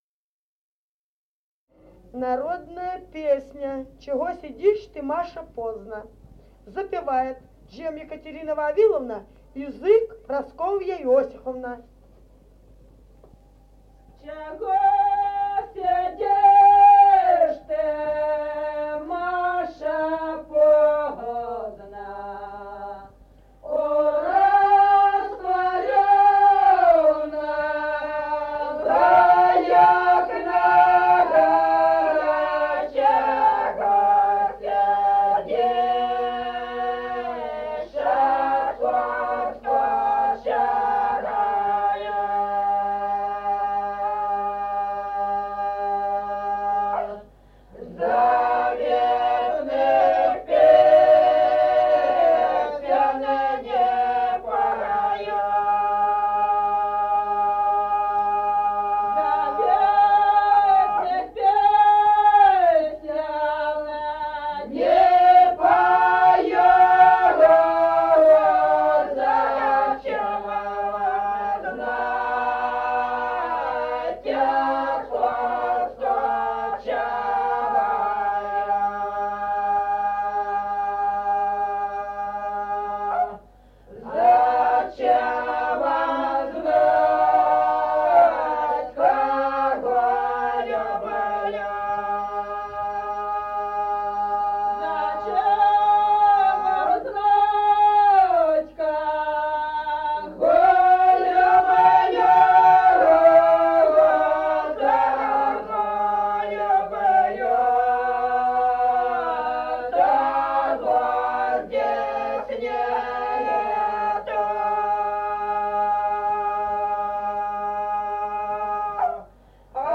с. Остроглядово.